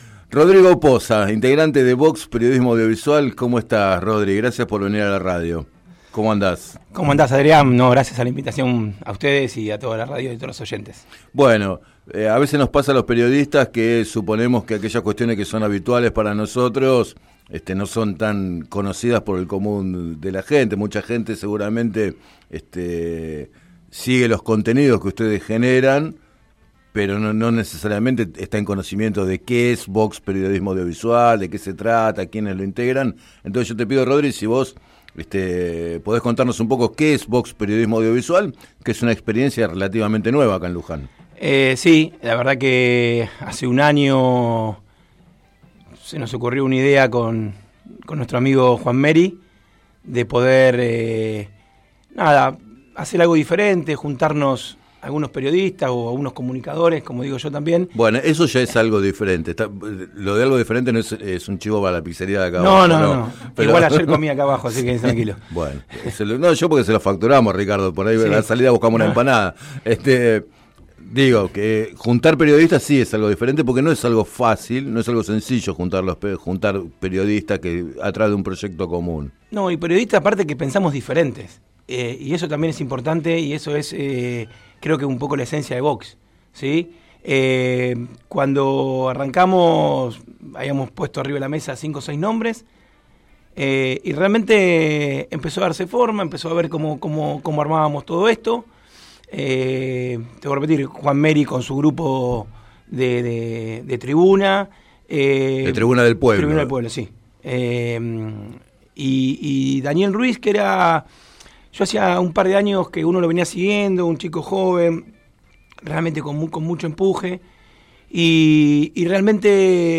Entrevistado en el programa Planeta Terri de FM Líder 97.7